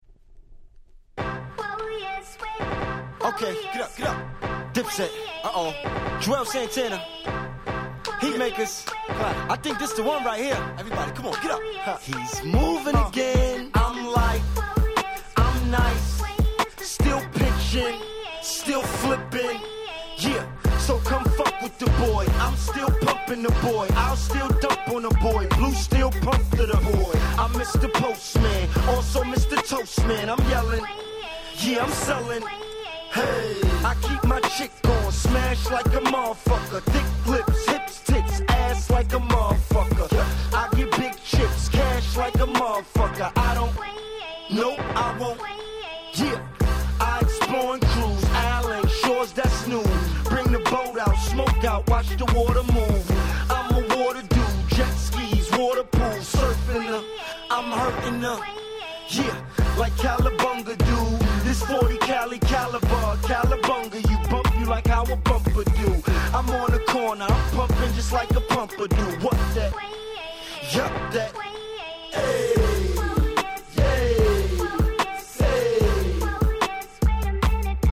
05' Smash Hit Hip Hop !!
当時流行った「早回し系サンプリング」の完成系。